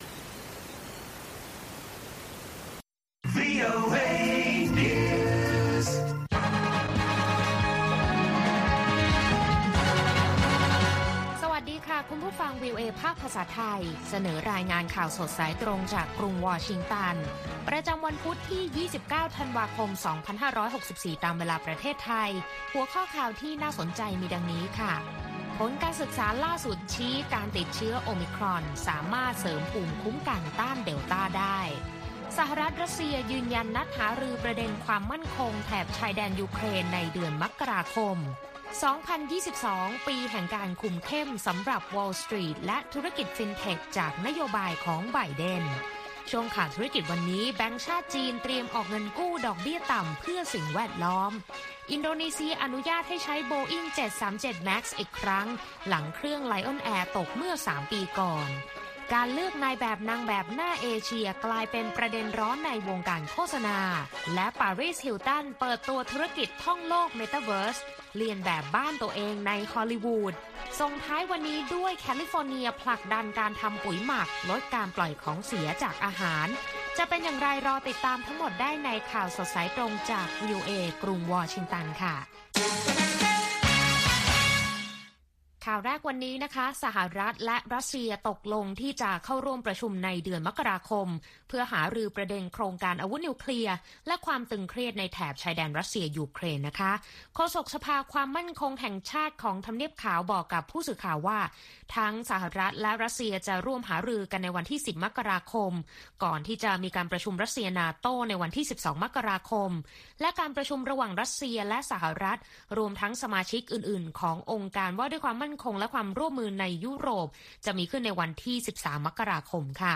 ข่าวสดสายตรงจากวีโอเอ ภาคภาษาไทย ประจำวันพุธที่ 29 ธันวาคม 2564 ตามเวลาประเทศไทย